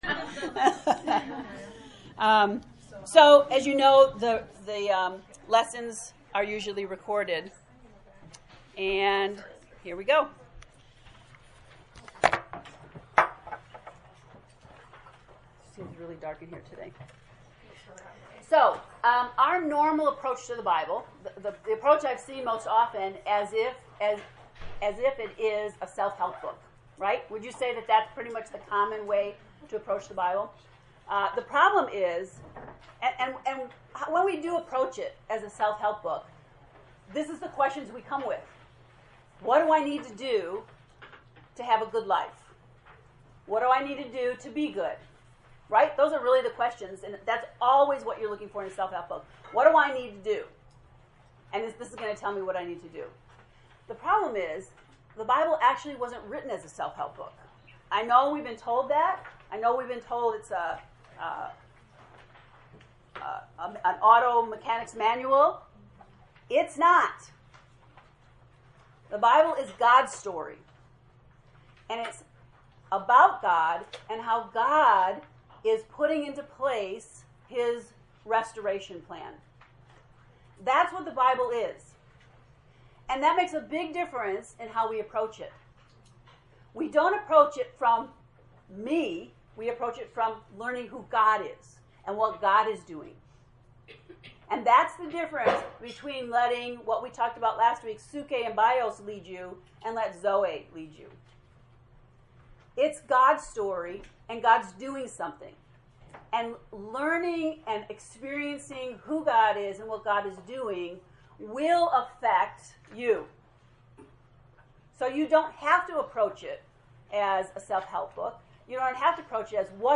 To listen to the lecture 0 “Noticing Zoe” click below: